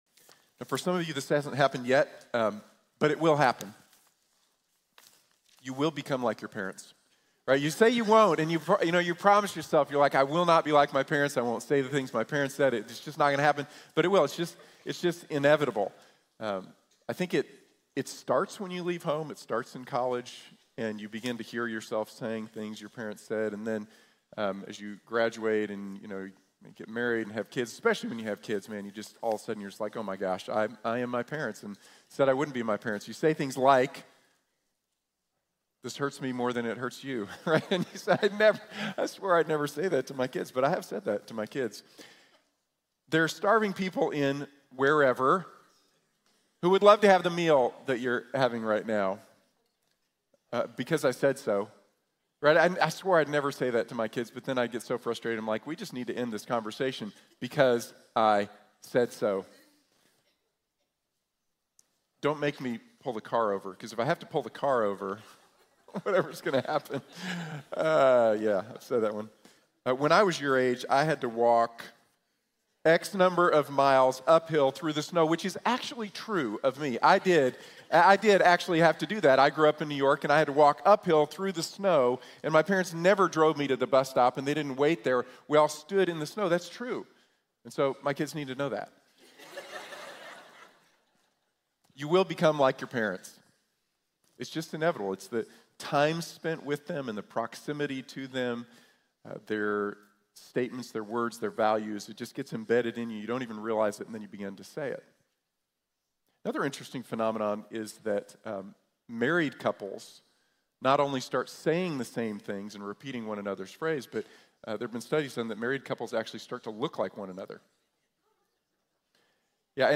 The Gospel is Good News | Sermon | Grace Bible Church